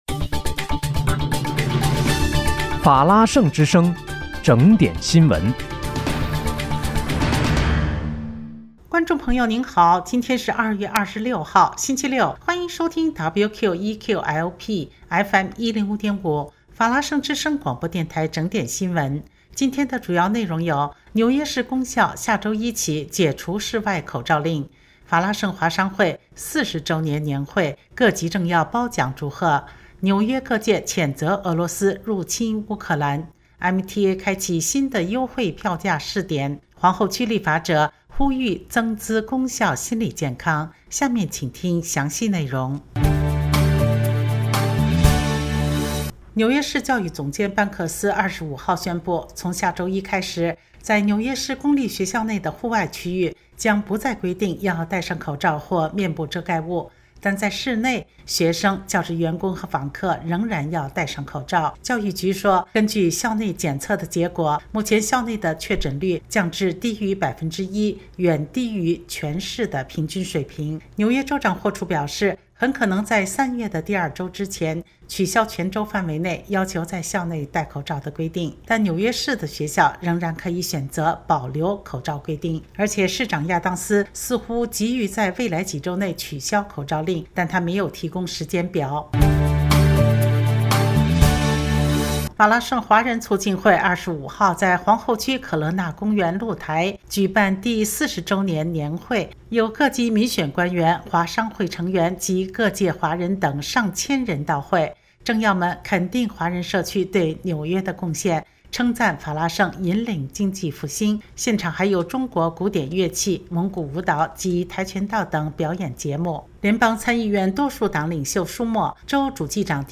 2月26日（星期六）纽约整点新闻